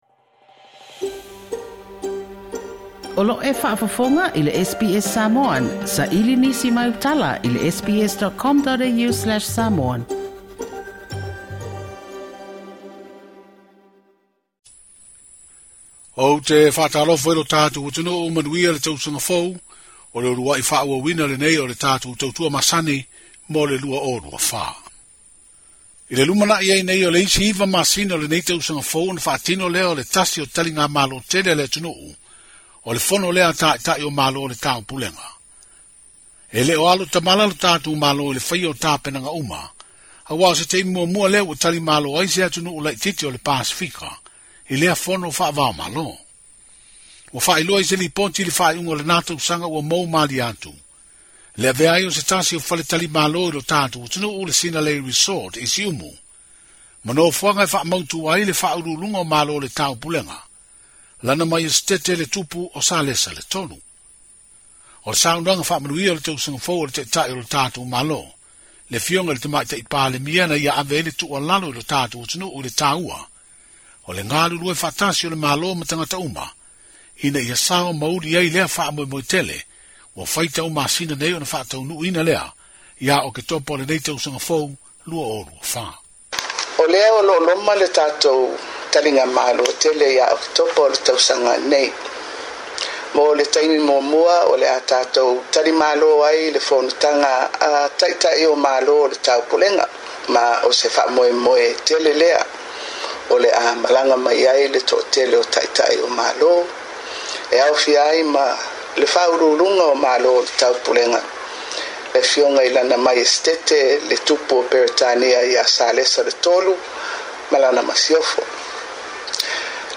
Saunoaga faamanuia a le palemia o Samoa, Fiame Naomi Mata'afa.